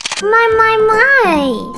Worms speechbanks
CollectArm.wav